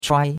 chuai1.mp3